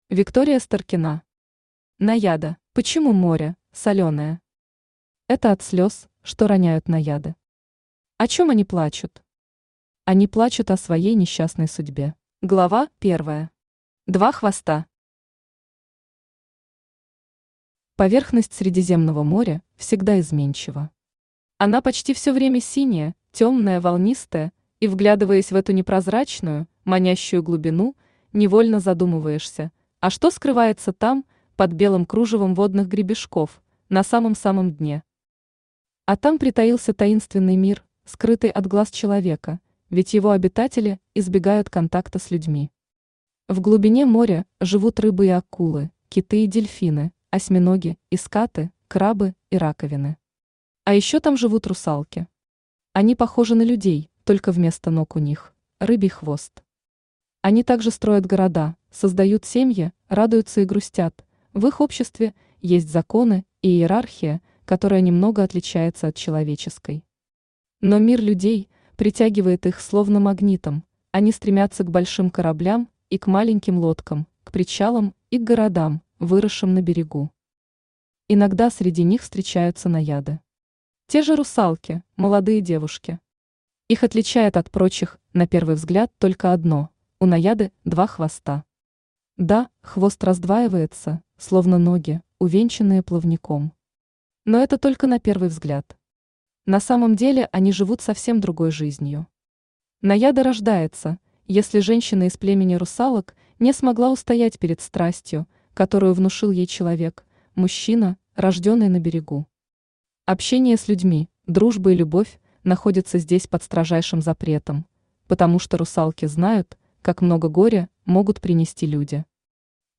Аудиокнига Наяда | Библиотека аудиокниг
Aудиокнига Наяда Автор Виктория Старкина Читает аудиокнигу Авточтец ЛитРес.